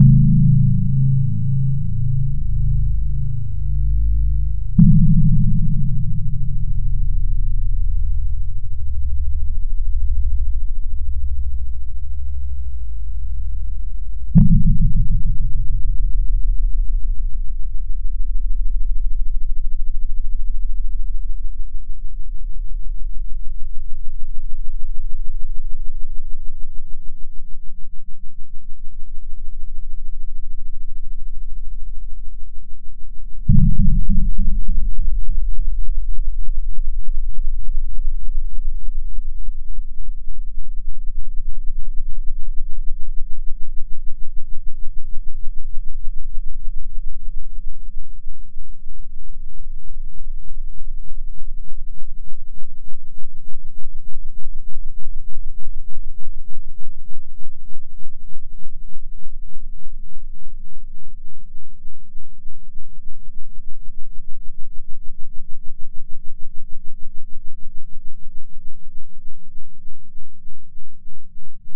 Amiga 8-bit Sampled Voice
Guitar.mp3